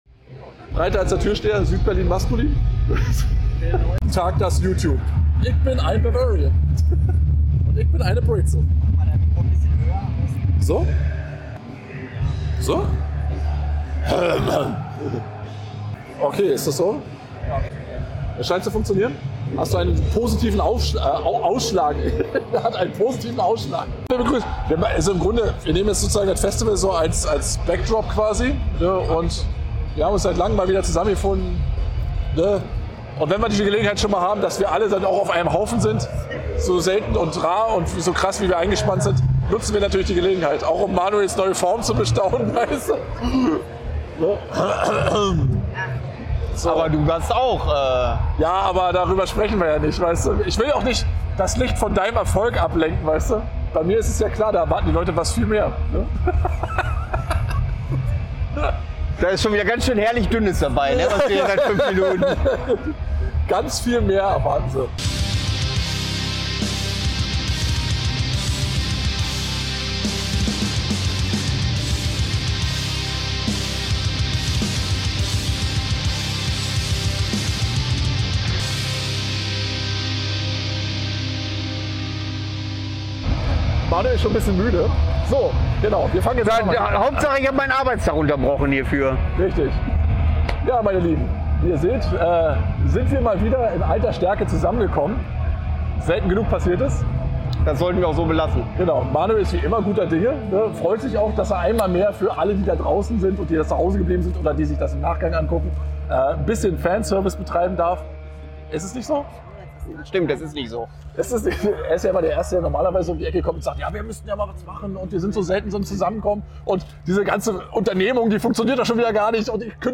Abgerundet wird das ganze mit einem Live-Eindruck von Naxen beim diesjährigen UTBS.